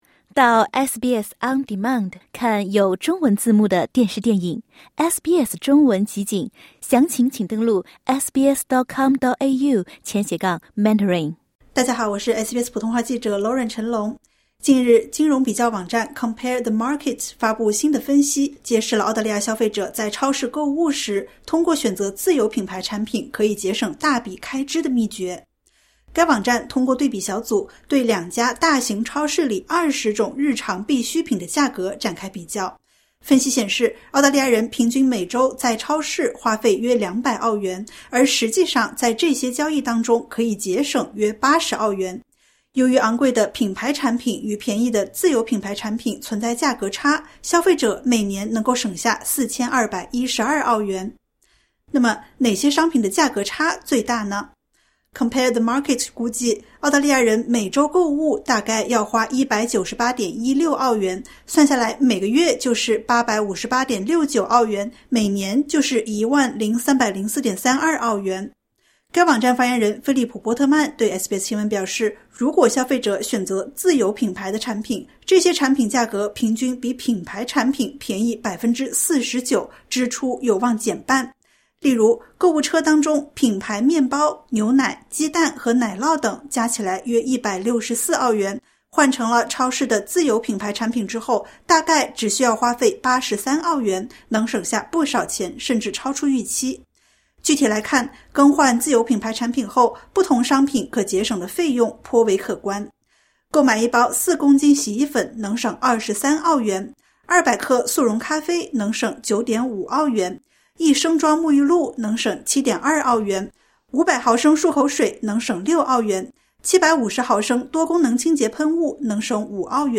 一个对比小组对两家大型超市里20种日常必需品的价格做了比较，结果如下。点击 ▶ 收听完整报道。